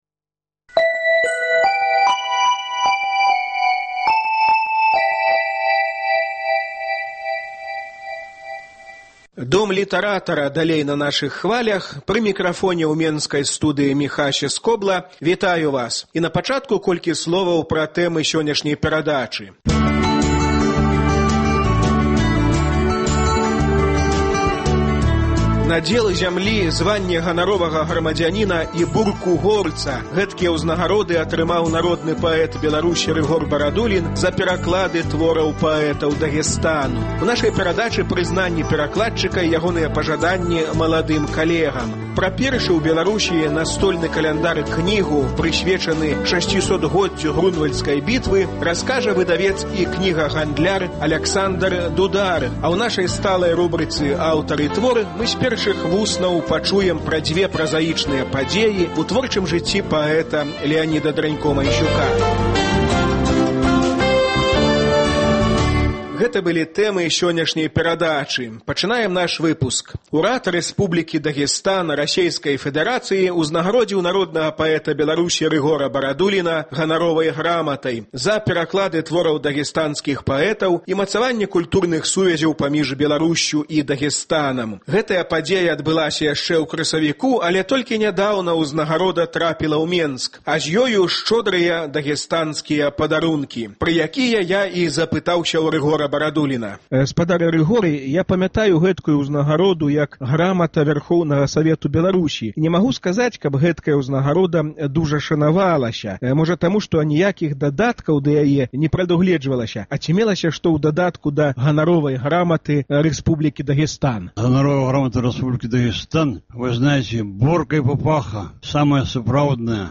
Гутарка
І на заканчэньне Леанід Дранько-Майсюк прачытае свае новыя вершы.